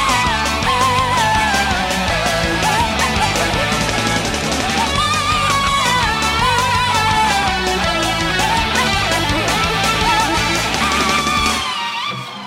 merkwürdige Adaption von einem Haydn-Quartett.